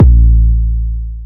808 (Plays).wav